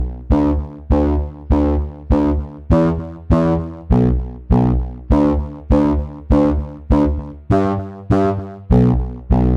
Dance music bass loop - 100bpm 63